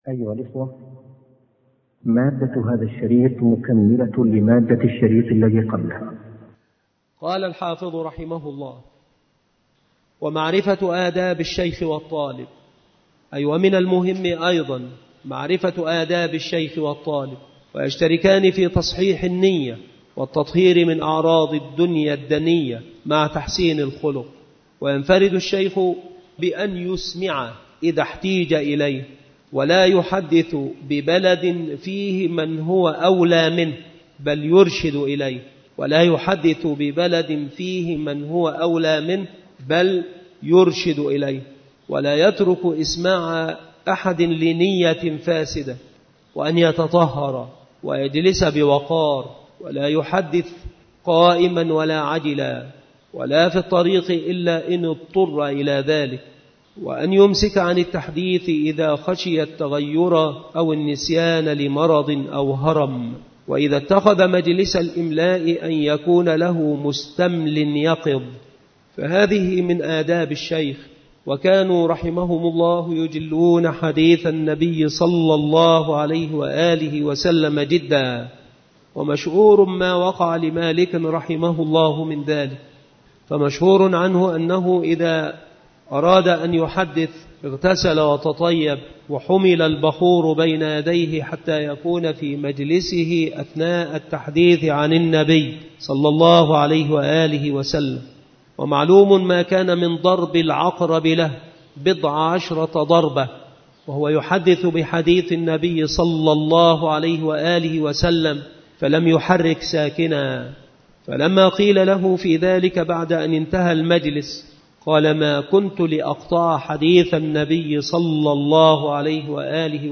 مكان إلقاء هذه المحاضرة بالمسجد الشرقي بسبك الأحد - أشمون - محافظة المنوفية - مصر عناصر المحاضرة : آداب طالب العلم.